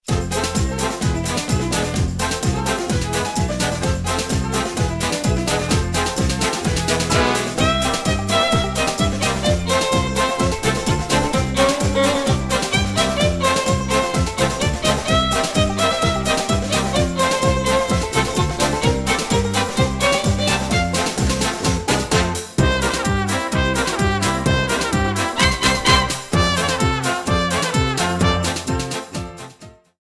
Trimmed, normalized and added fade-out.